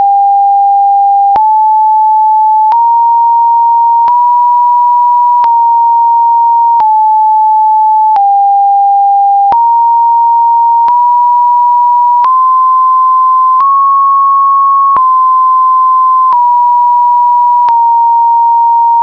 等比数列のものはオクターブ違っていても、同じメロディーであるということがわかります。